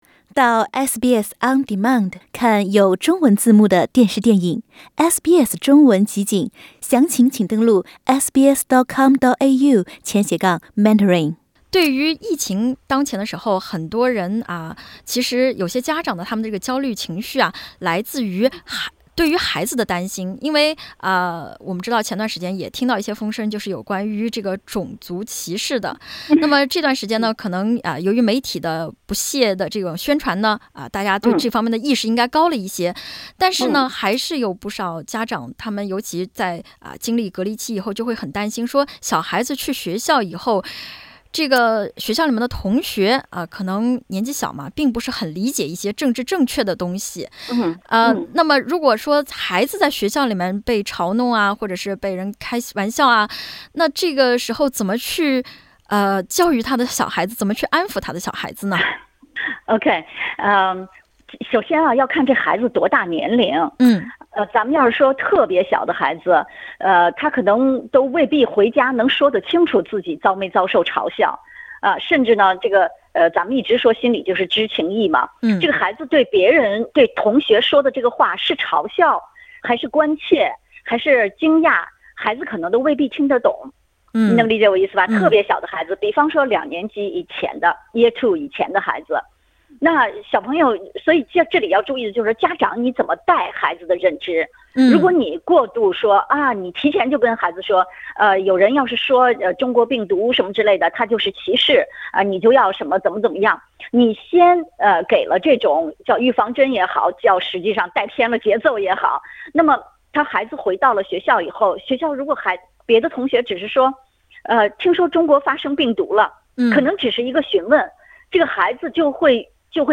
欢迎点击封面图片收听详细采访。